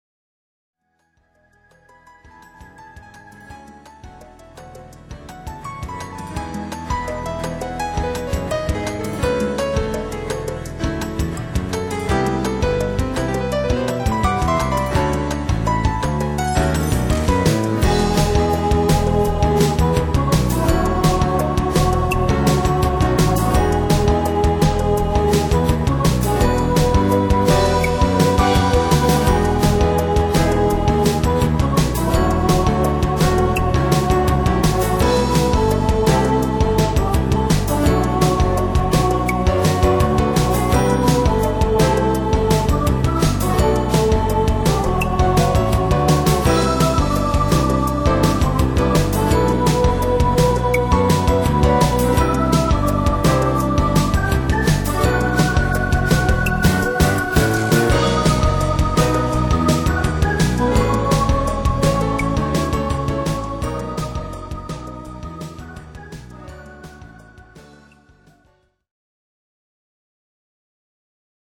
感想　私にしてはめずらしくメジャー（長調）の曲で、なんか底抜けに明るい(^o^)！